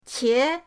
qie2.mp3